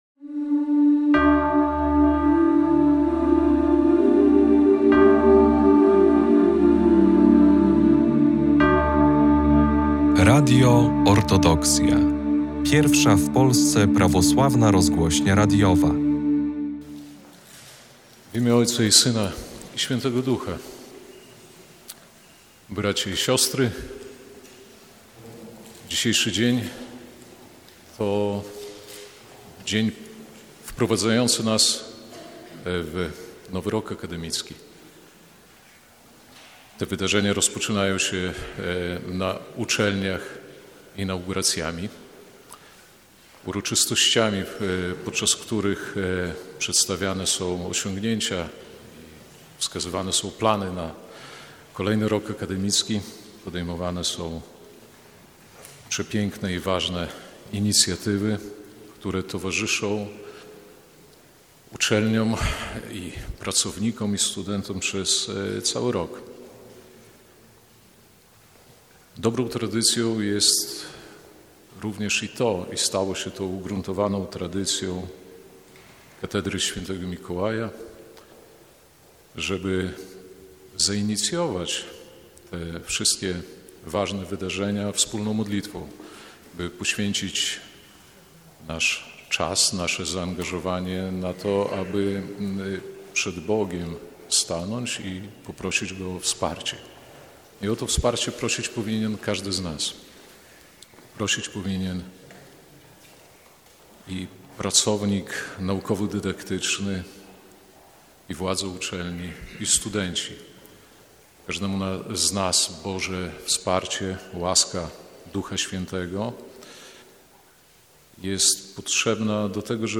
30 września, JE Najprzewielebniejszy Jakub Arcybiskup Białostocki i Gdański odprawił w białostockiej Katedrze św. Mikołaja, molebien połączony ze śpiewanym akatystem ku czci św. męcz.